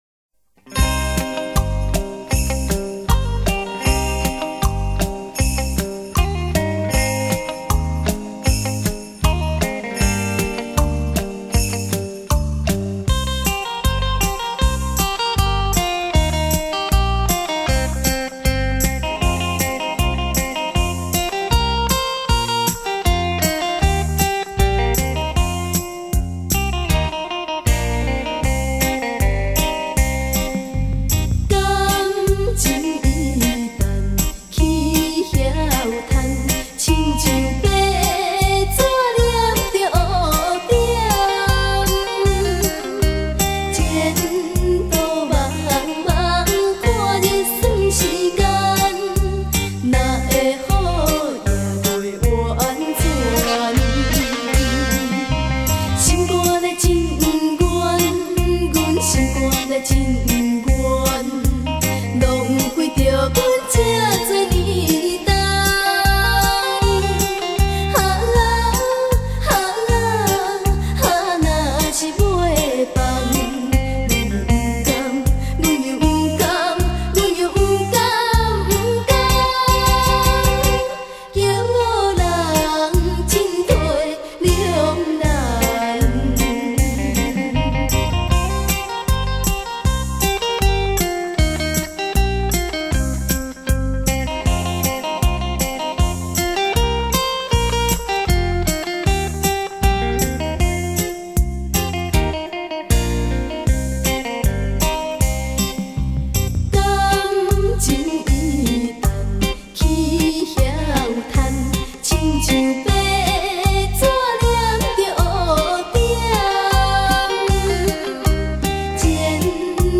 悠扬双吉他声中
悠扬的乐声